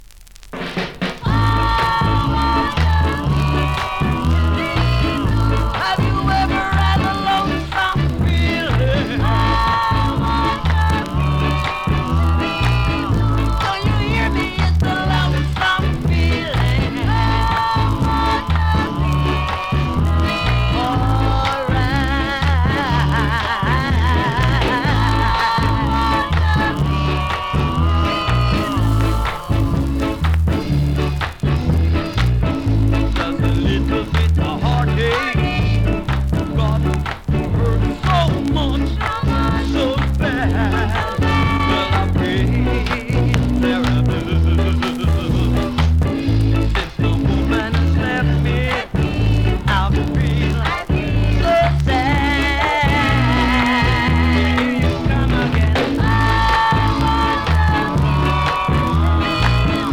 2019 NEW IN!!SKA〜REGGAE!!
スリキズ、ノイズ比較的少なめで